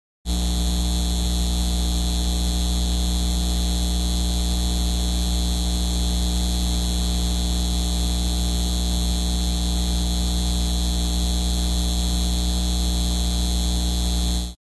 Звуки фонарей
Скачивайте или слушайте онлайн эффекты зажигания, ровного гудения и тихого потрескивания.